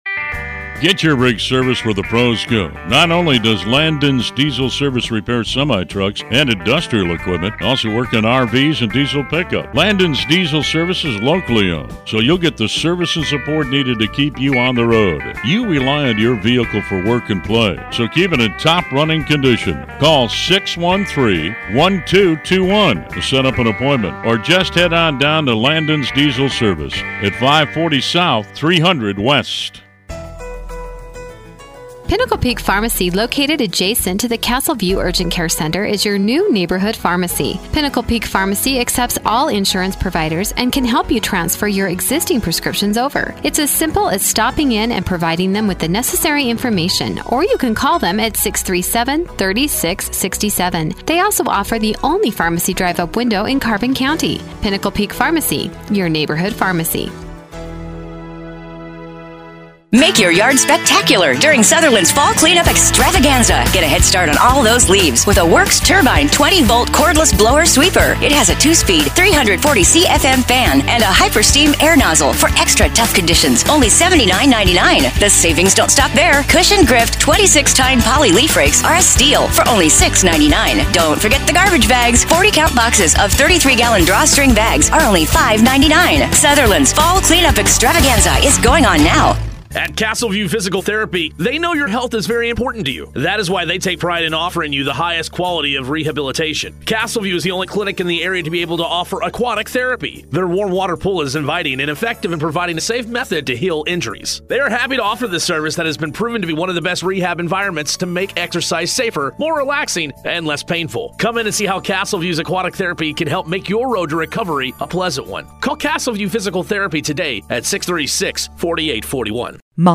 was a guest on KOAL’s Drive Time Sports